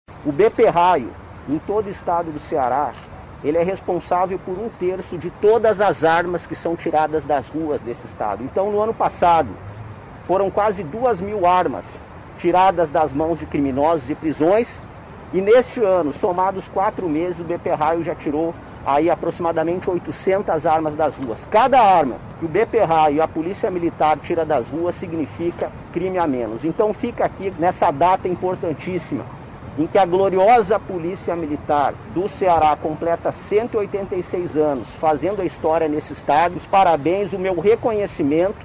O titular da SSPDS, Sandro Caron, frisou que o CPRaio atua firmemente no combate à criminalidade, contribuindo para fortalecer a atuação e resultados da PMCE.